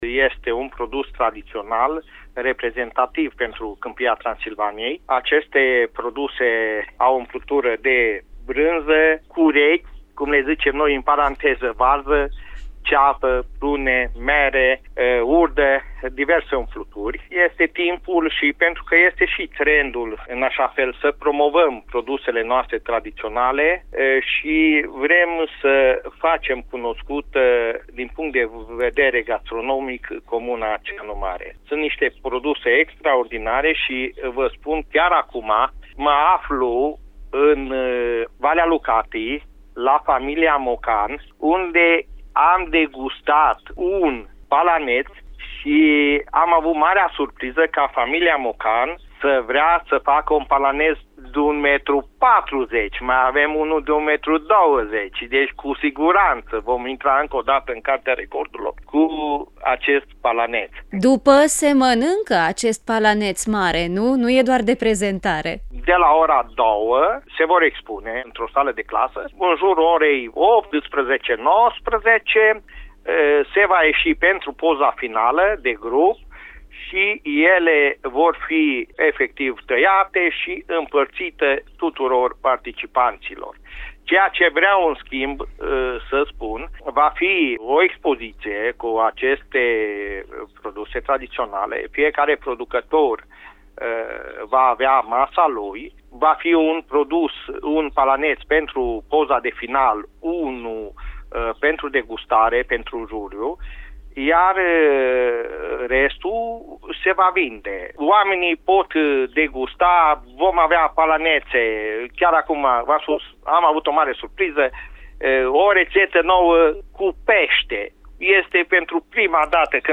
Primarul comunei Ceanu Mare, Virgil Păcurar, spune că evenimentul este un prilej de mândrie locală, dar şi o ocazie excelentă de a promova gastronomia tradiţională a Câmpiei Transilvaniei.